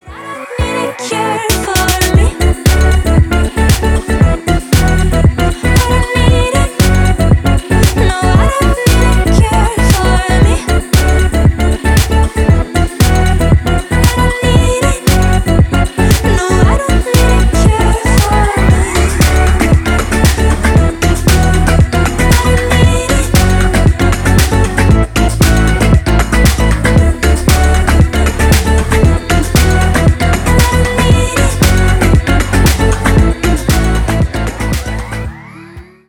• Качество: 320, Stereo
поп
спокойные
красивый женский голос